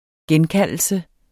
Udtale [ ˈgεnˌkalˀəlsə ]